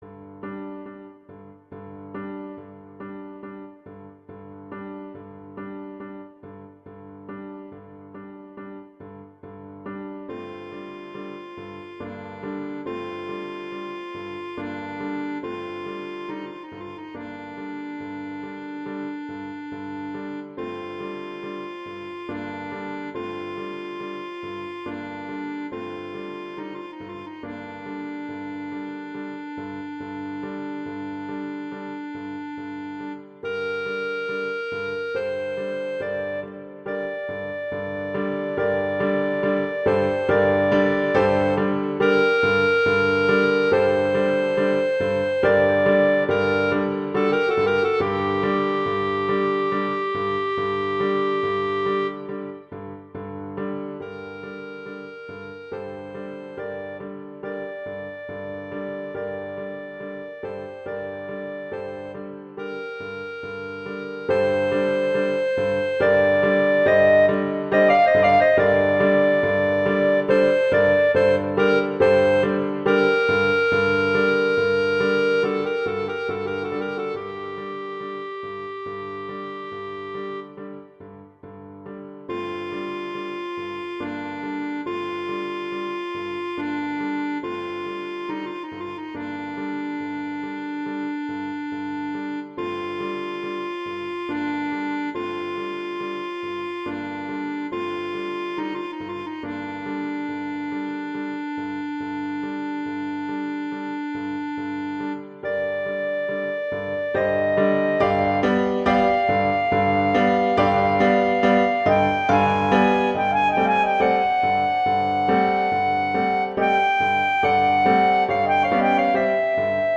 arrangement for clarinet and piano
clarinet and piano
classical, holiday